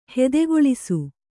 ♪ hedegoḷisu